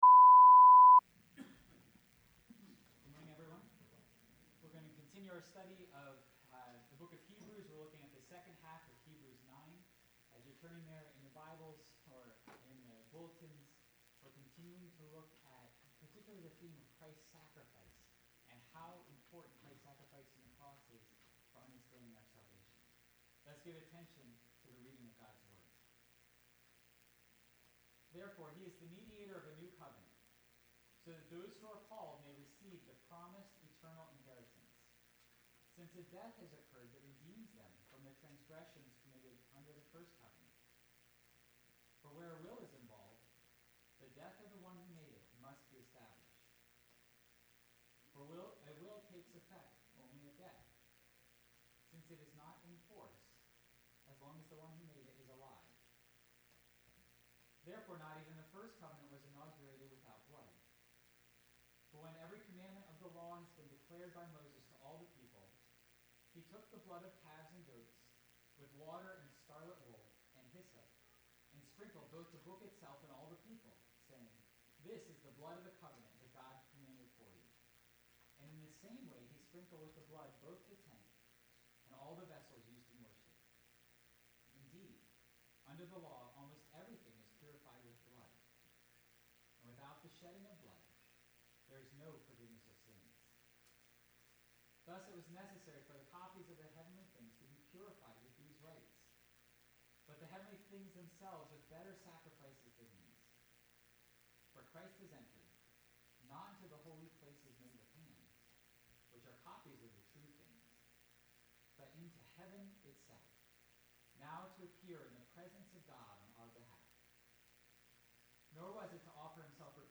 5.16.21-sermon-audio.mp3